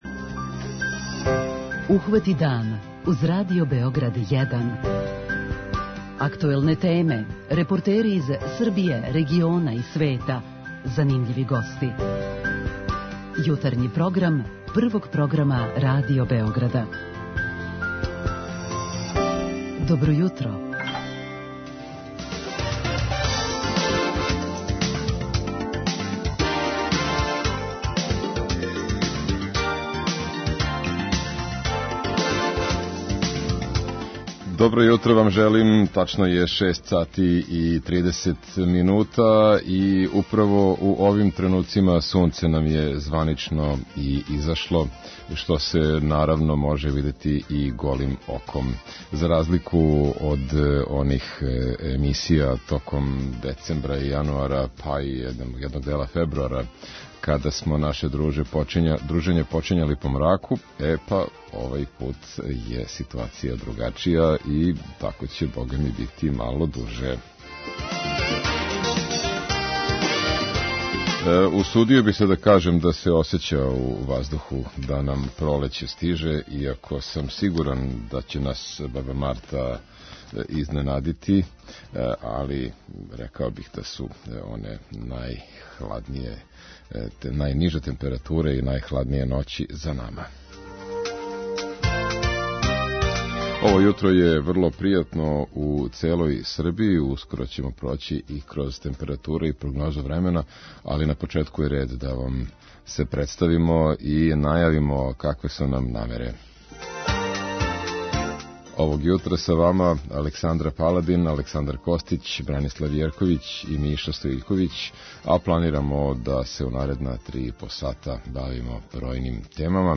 преузми : 37.78 MB Ухвати дан Autor: Група аутора Јутарњи програм Радио Београда 1!